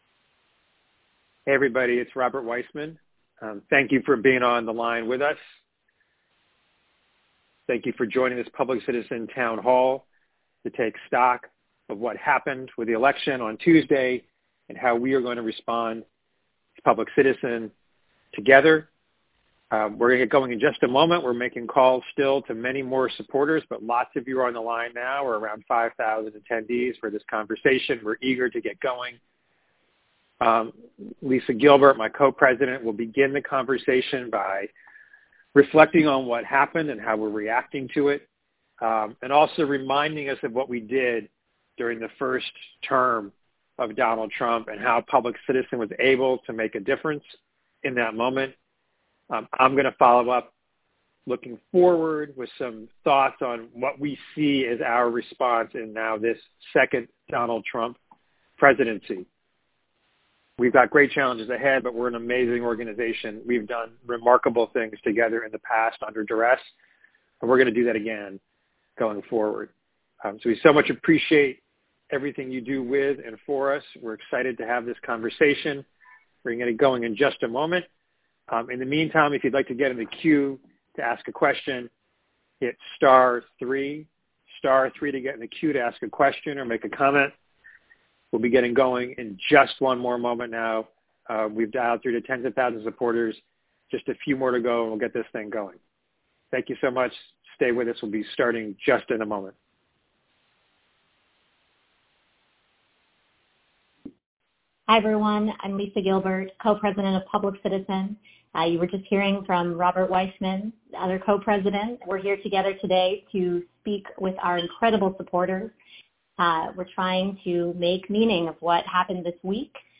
Public Citizen Post-Election Town Hall
Post-Election-Town-Hall-Recording.mp3